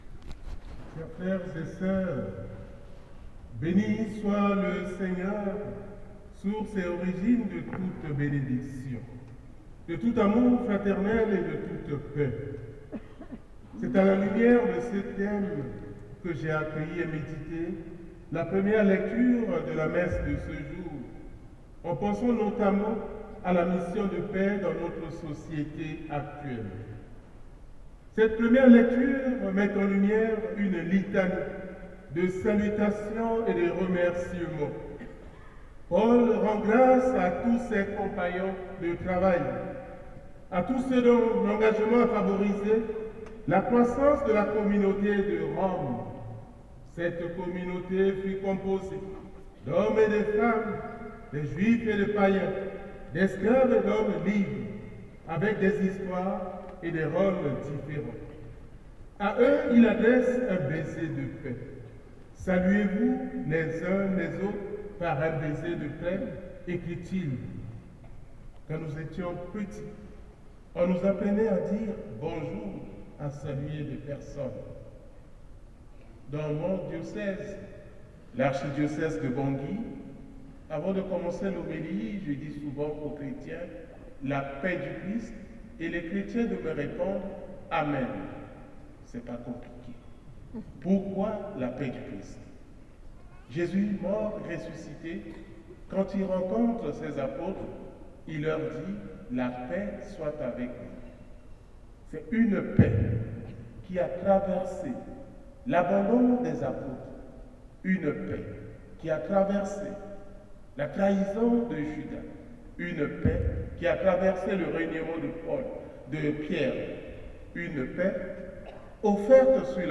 🎧Homélie Mgr Dieudonné Nzapalainga
• Samedi - 9h - Messe - Homélie Mgr Dieudonné Nzapalainga